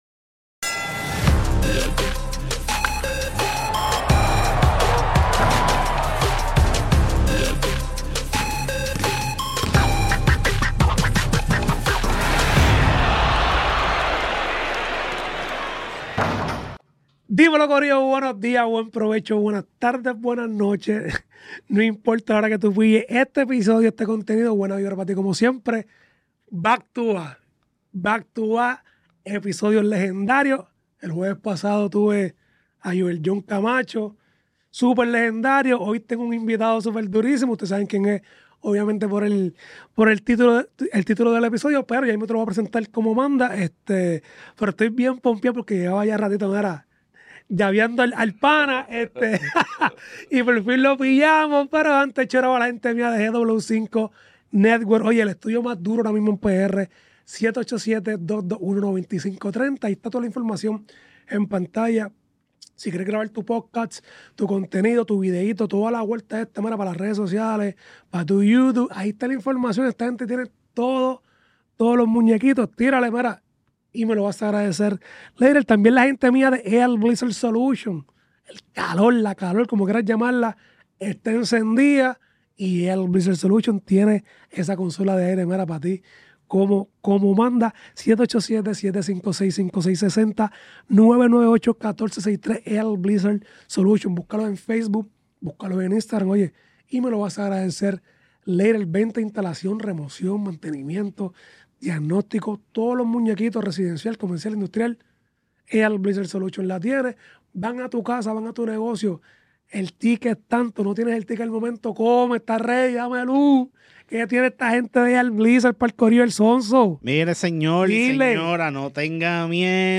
Grabado en GW5 Studio